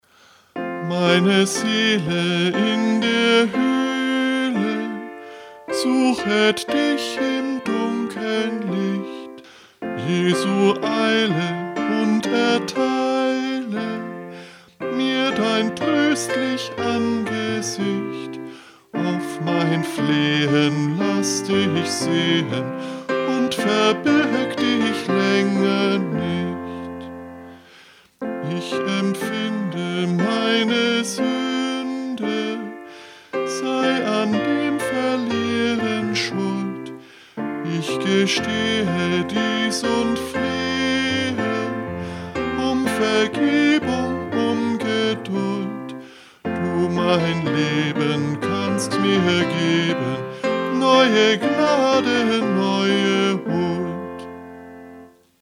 Liedvortrag